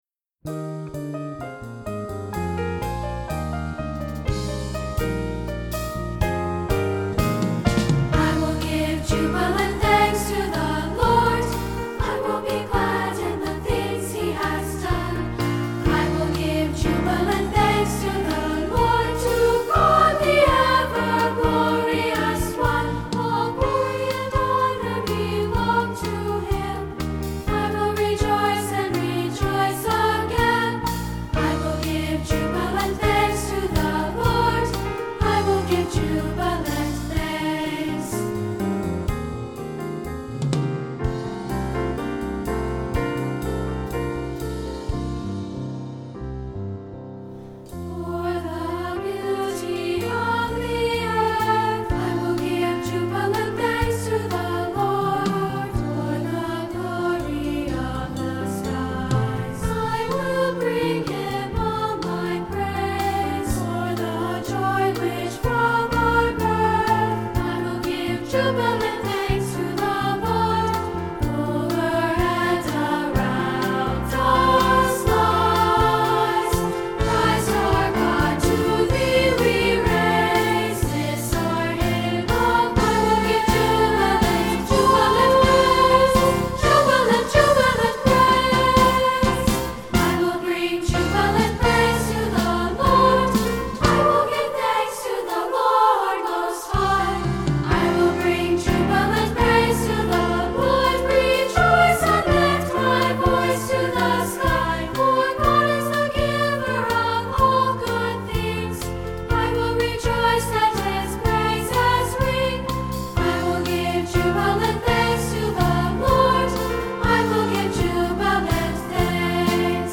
Voicing: SA and Piano